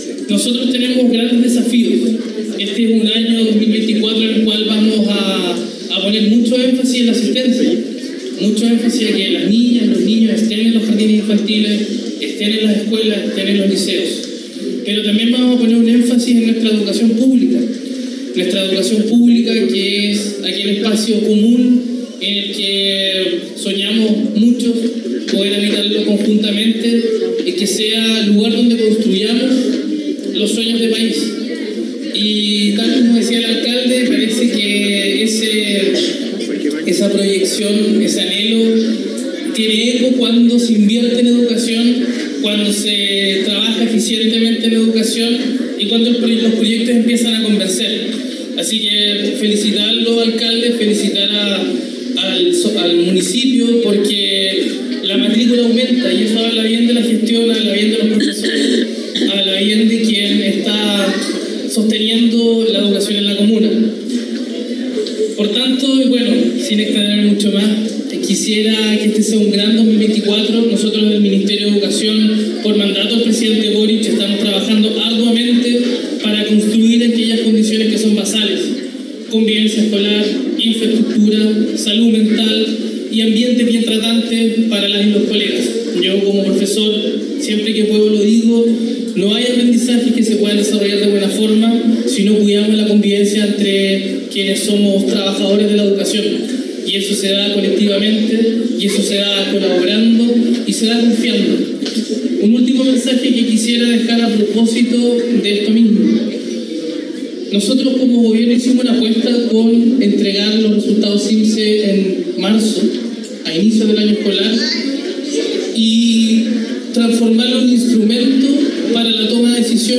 Con la ceremonia de inauguración de obras, se realizó el hito inicial del año escolar 2024 para la comuna de Futrono, relevando la inversión del Ministerio de Educación que supera los 277 millones.